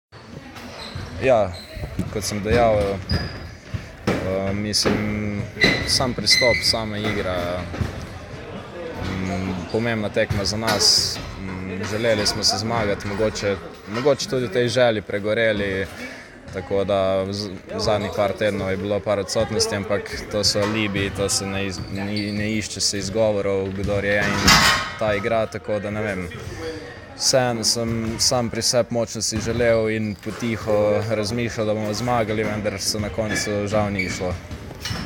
Izjavi po tekmi: